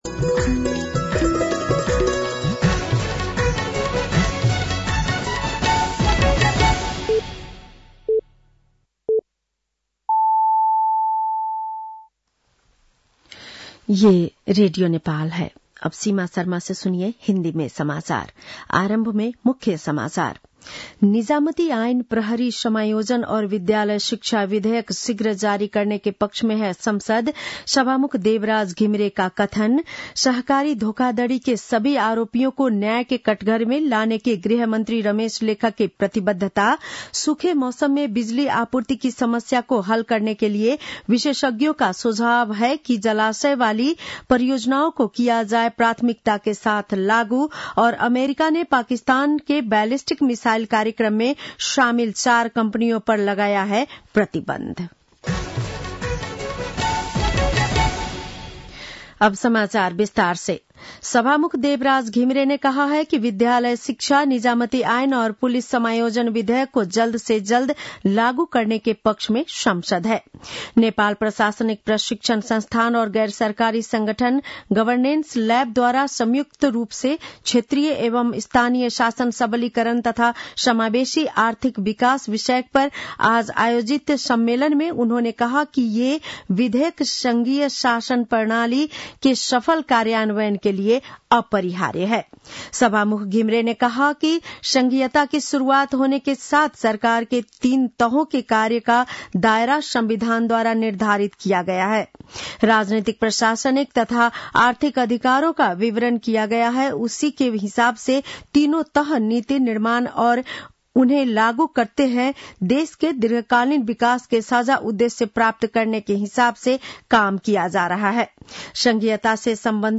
बेलुकी १० बजेको हिन्दी समाचार : ५ पुष , २०८१
10-PM-Hindi-NEWS-9-04.mp3